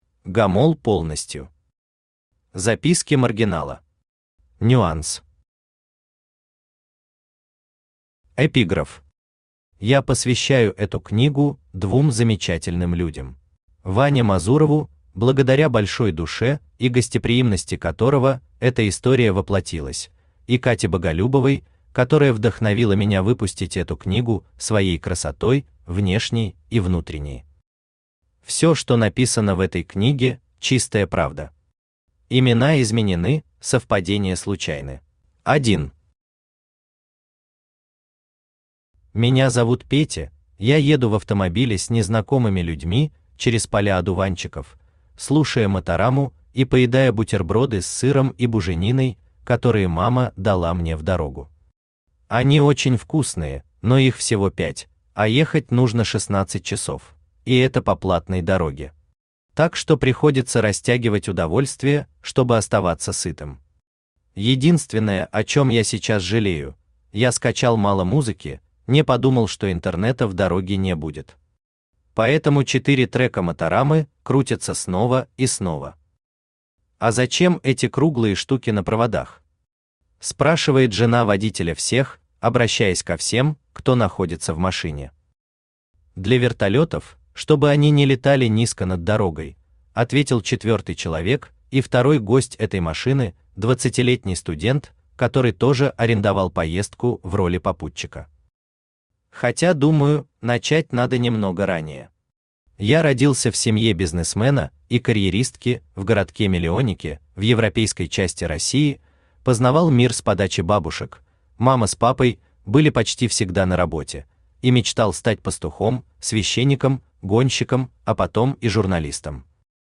Аудиокнига Записки маргинала. Нюанс | Библиотека аудиокниг
Нюанс Автор Гамол Полностью Читает аудиокнигу Авточтец ЛитРес.